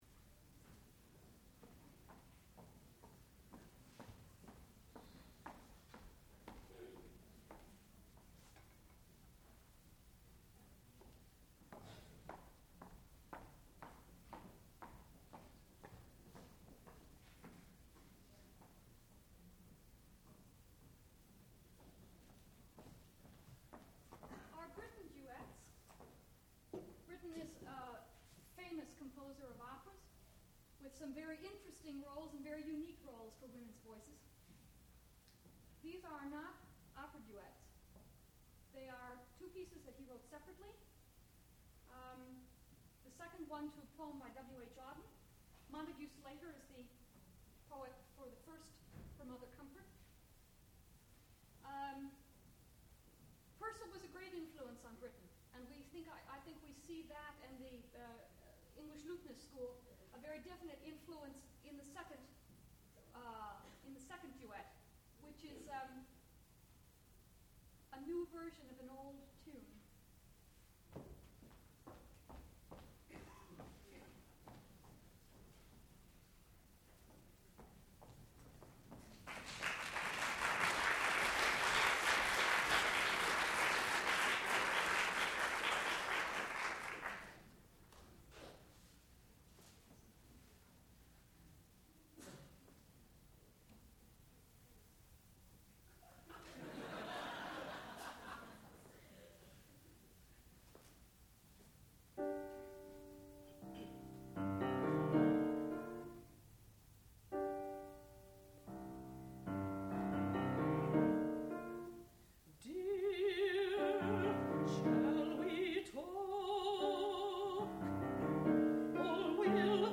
sound recording-musical
classical music
soprano
mezzo-soprano
piano